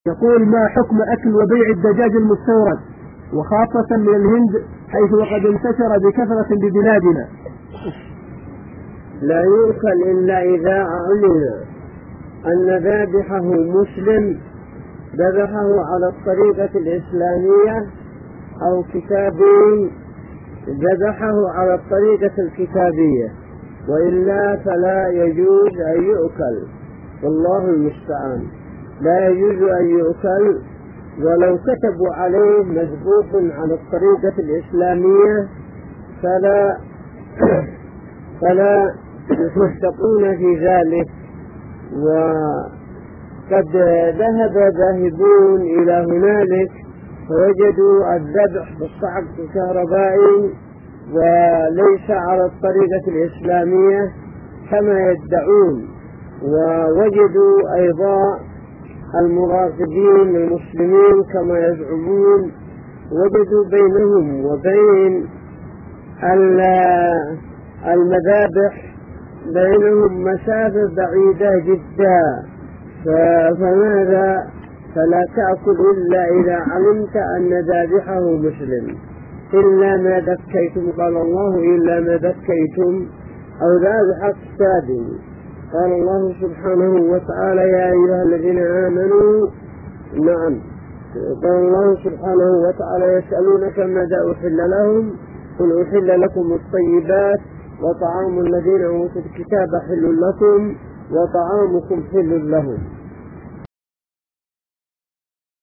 | فتاوى الشيخ مقبل بن هادي الوادعي رحمه الله